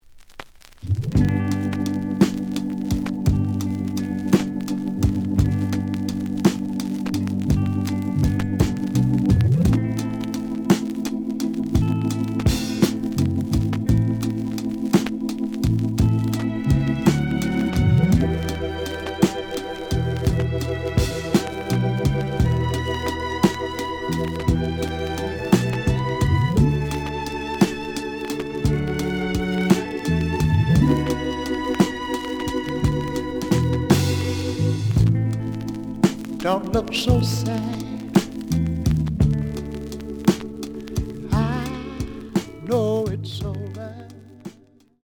The audio sample is recorded from the actual item.
●Genre: Soul, 70's Soul
Some noise on A side.